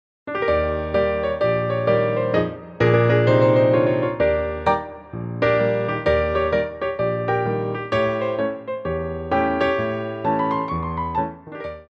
Grands Battements
4/4 (16x8)